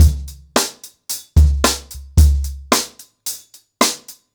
HarlemBrother-110BPM.5.wav